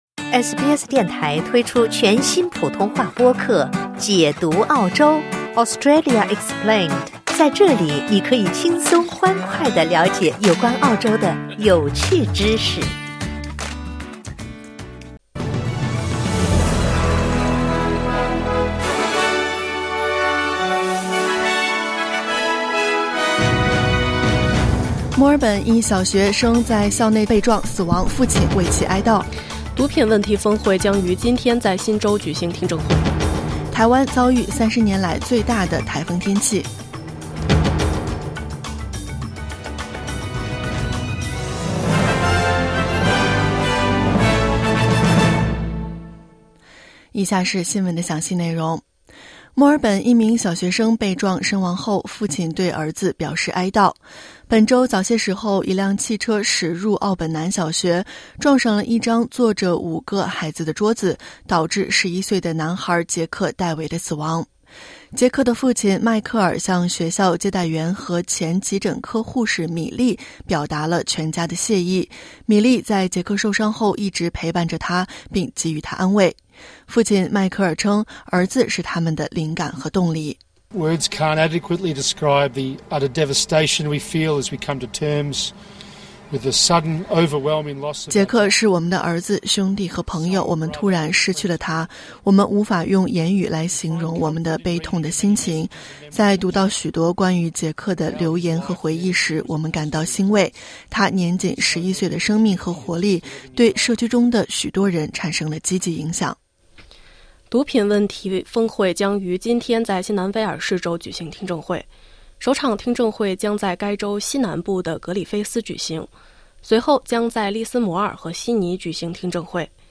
SBS早新闻（2024年11月1日）